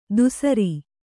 ♪ dusari